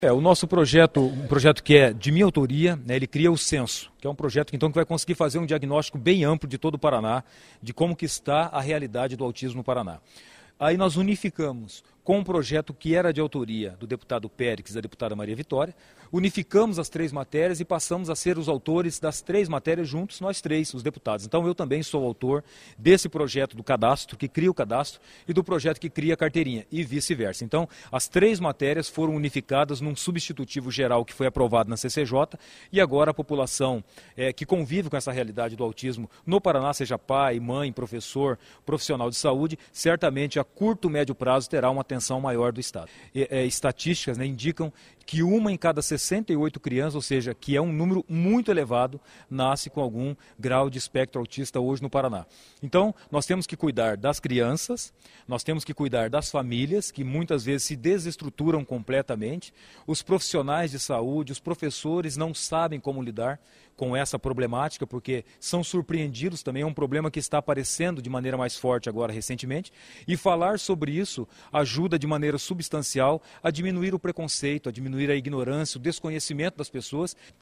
O projeto,  que pretende fazer um cadastro dos portadores do transtorno do Espectro Autista (TEA), surgiu da soma de propostas dos deputados Maria Victòria (PP), Péricles de Mello (PT) e Márcio Pacheco (PPL). Ouça a entrevista do deputado Márcio Pacheco sobre o que vai representar essa mudança para as famílias e portadores.